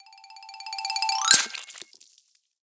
34 KB Mr. Sandman kill sound 1
Npc_human_perk_mrsandman.ogg